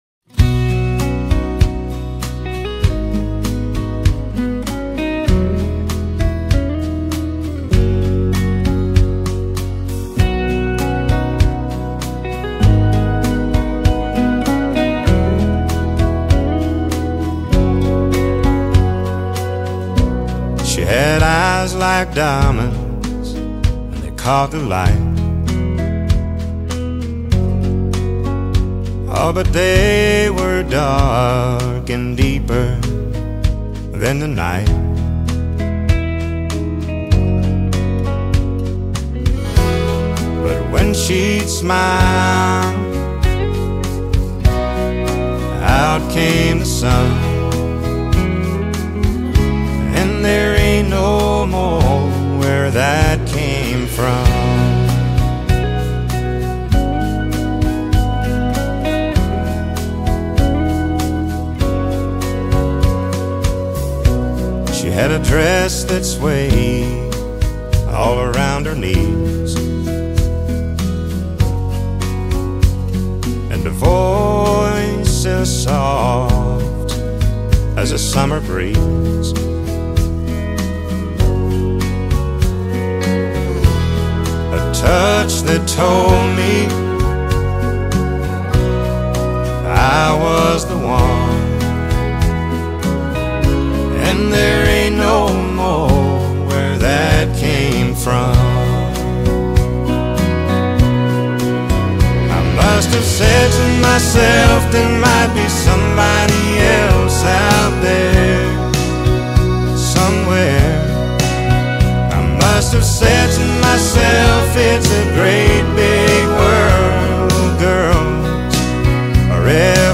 Country Song
It doesn’t matter if it’s AI, if it’s voice cloning, etc.